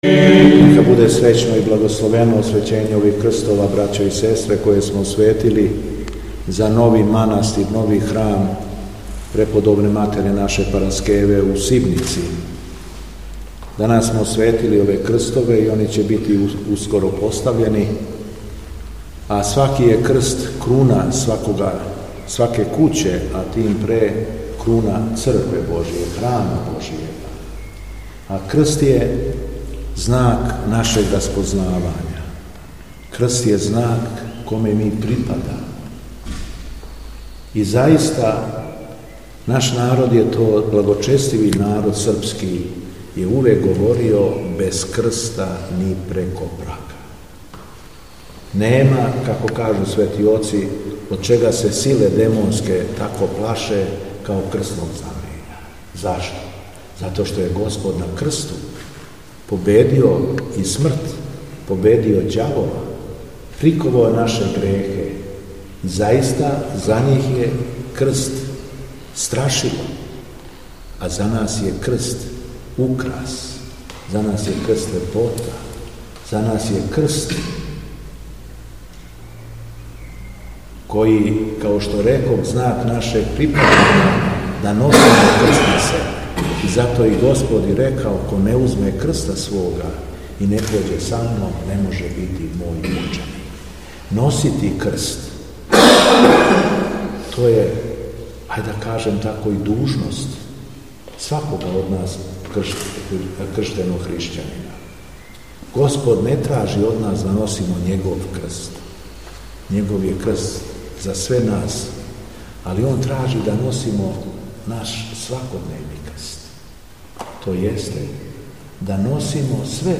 У суботу прву по Духовима, Његово Преосвештенство Епископ шумадисјки Г. Јован је у поподневним часовима, у храму Светих апостола Петра и Павла у Неменикућама, освештао крстове за храм Преподобне матере Параскеве, који се такође налази у овоме селу.
Беседа Његовог Преосвештенства Епископа шумадијског г. Јована